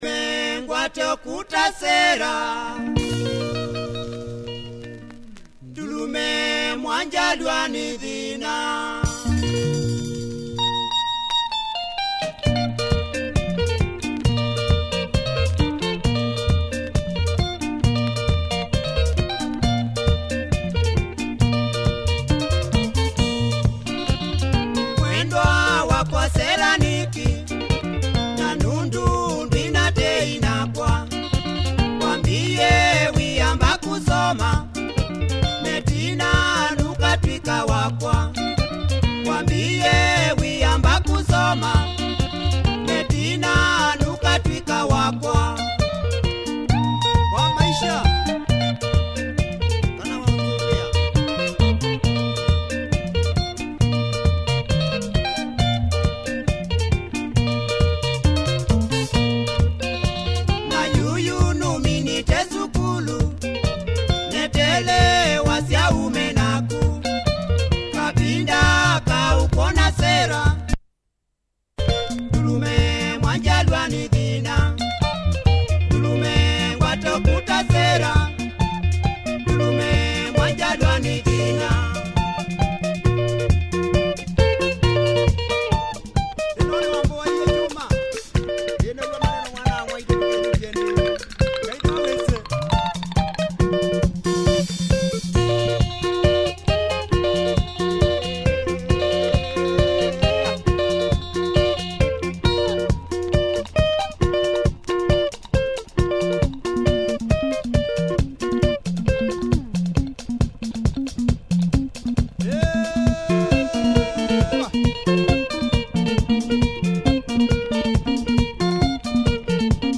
Heavy kamba benga, check audio for both sides. https